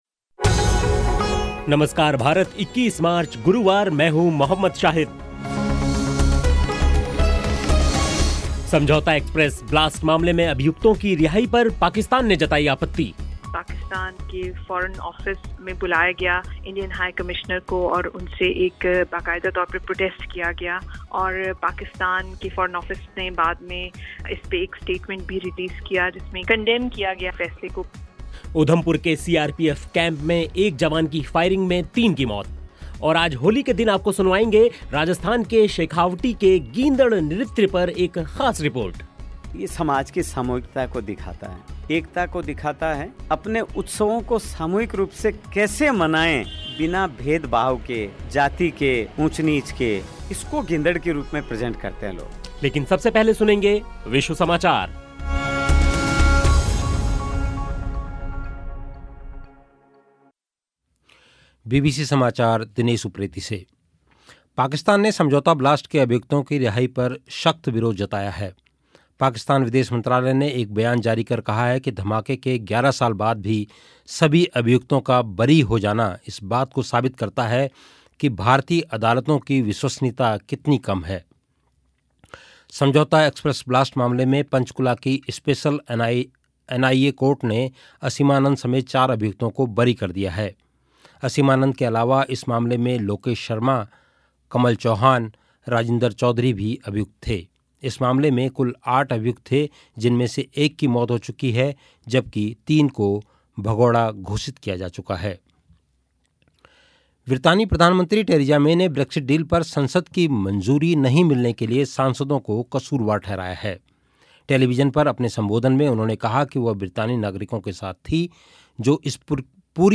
और आज होली के दिन आपको सुनवाएंगे राजस्थान के शेखावटी के गींदड़ नृत्य पर एक ख़ास रिपोर्ट. साथ ही होगी अख़बारों की समीक्षा भी लेकिन सबसे पहले विश्व समाचार सुनते हैं.